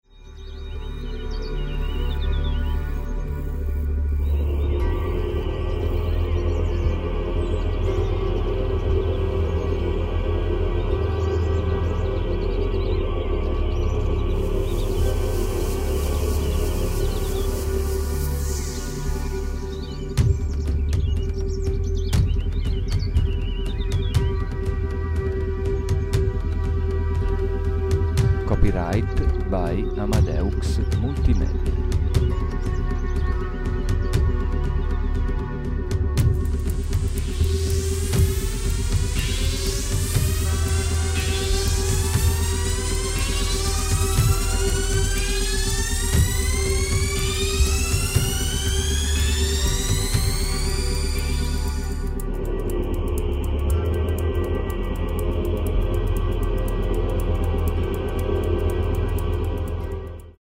Armonizzazione Kundalini 432 Hz + ASMR